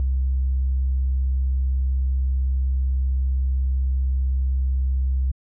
12kb - murda sub.wav